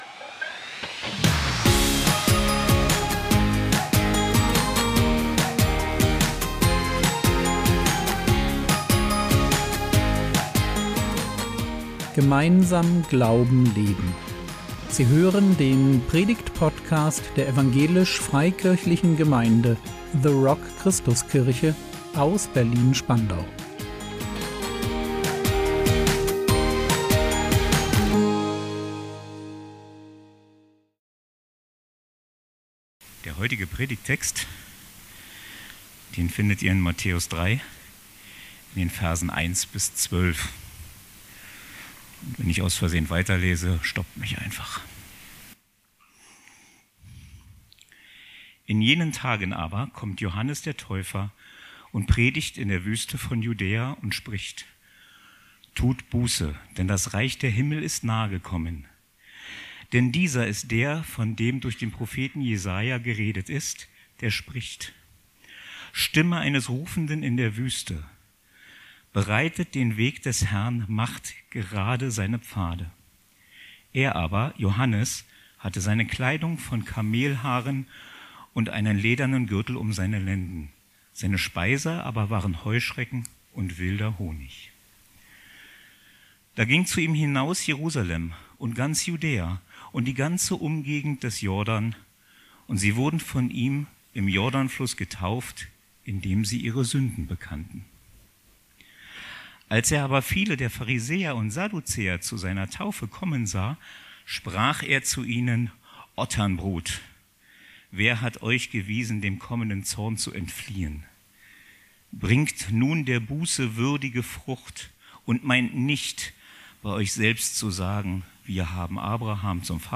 Umkehr als Lebenshaltung | 04.01.2026 ~ Predigt Podcast der EFG The Rock Christuskirche Berlin Podcast